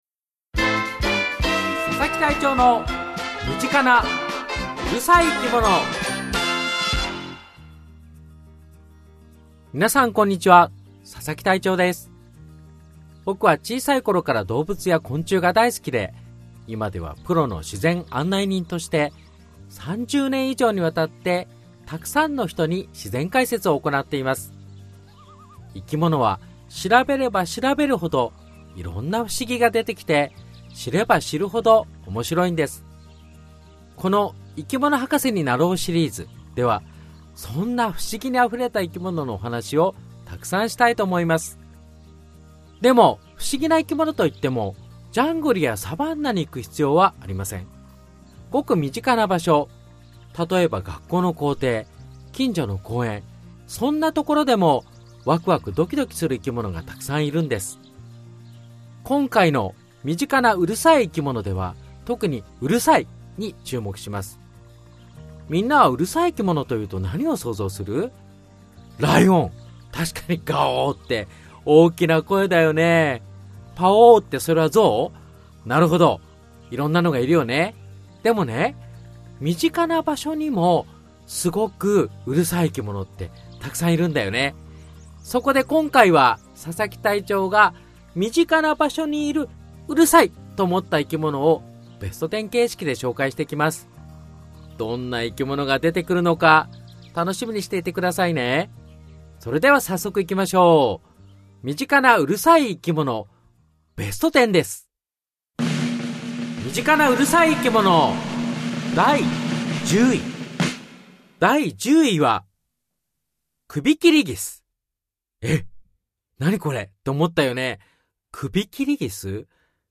知れば知るほど面白い生き物の世界をユーモアたっぷりに解説！